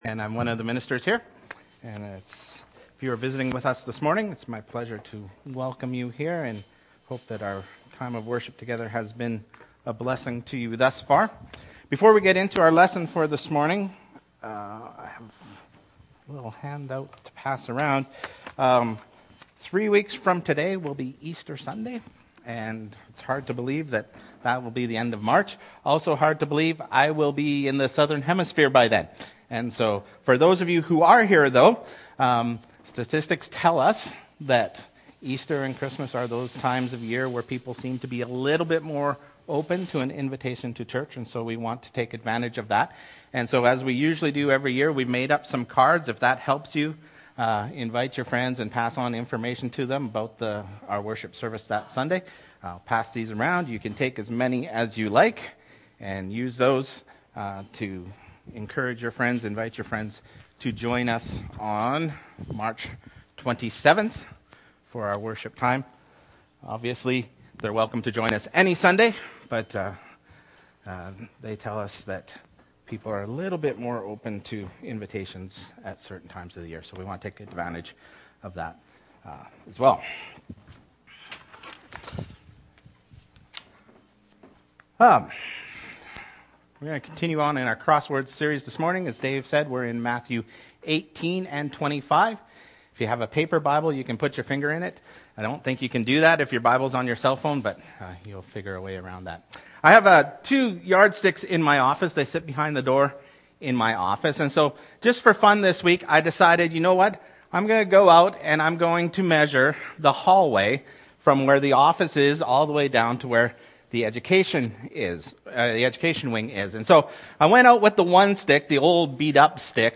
Sermon Series | Church of Christ Saskatoon